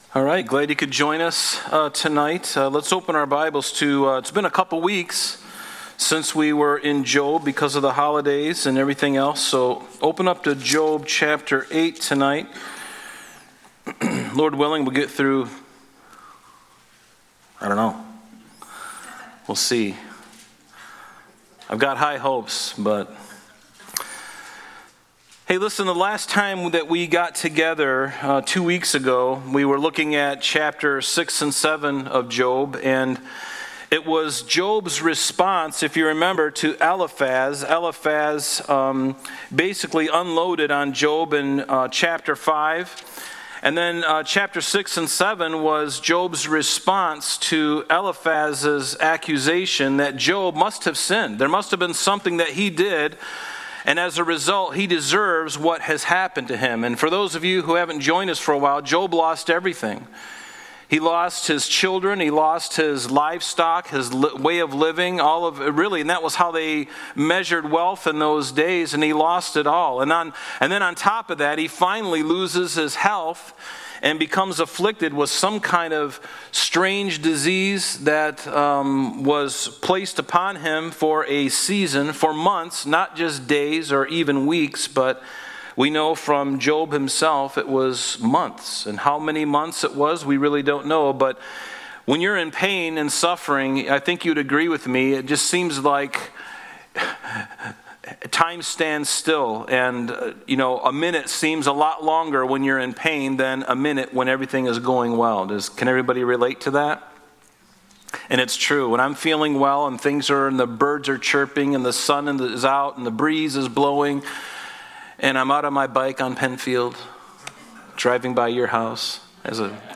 Thursday Night Bible Study